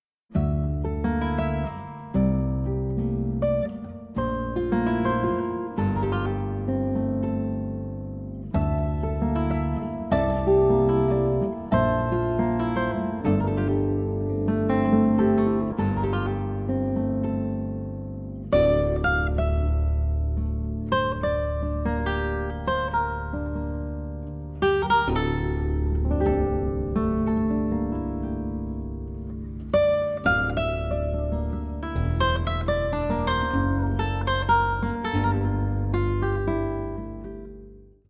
16-string classical guitar, 16-string Contraguitar